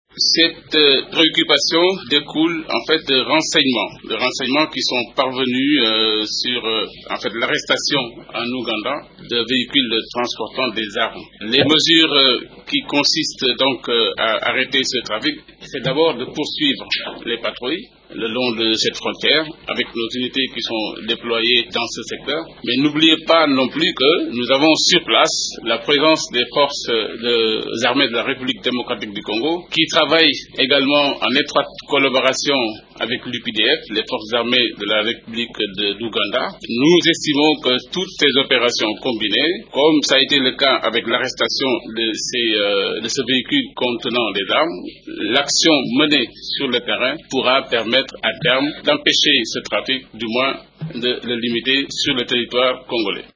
L’information a été livrée mercredi au cours de la conférence de presse hebdomadaire de la Monusco à Kinshasa.